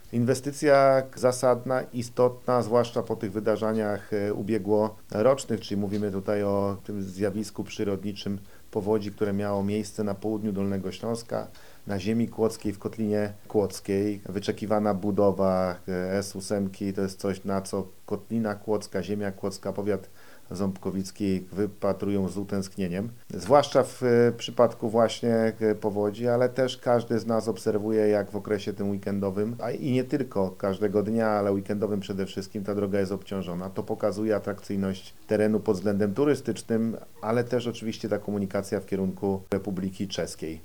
Marszałek Województwa Dolnośląskiego – Paweł Gancarz – podkreśla, że budowa drogi S8 w Kotlinie Kłodzkiej to bardzo ważna i potrzebna inwestycja.
na-strone_1_marszalek-o-budowie-drogi.mp3